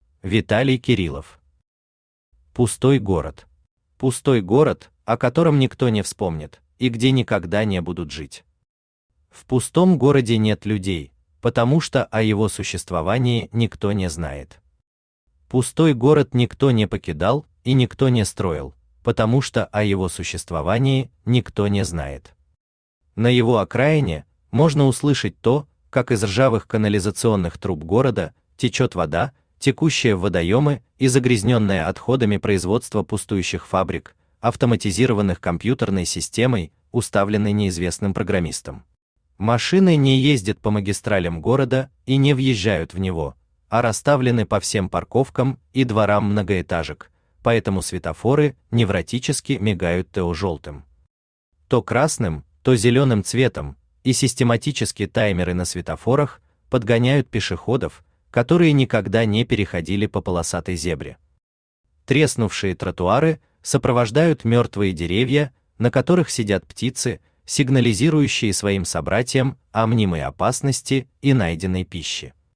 Аудиокнига Пустой город | Библиотека аудиокниг
Aудиокнига Пустой город Автор Виталий Александрович Кириллов Читает аудиокнигу Авточтец ЛитРес.